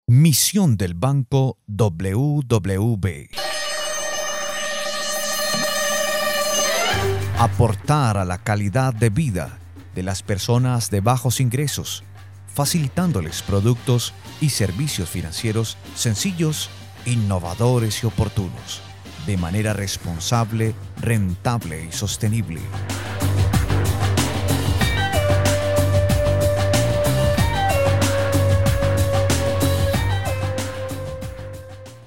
Masculino
Espanhol - América Latina Neutro
Institucional